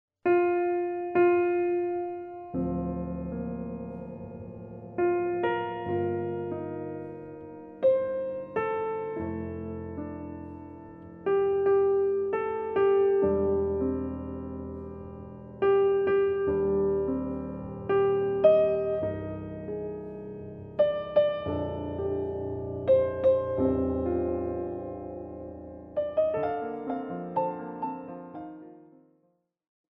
piano solo -